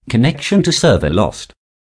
TeaWeb/shared/audio/speech/connection.disconnected.timeout.wav at ebcec46d2ae46658aff0cae1944d6a2c237ee1cc
connection.disconnected.timeout.wav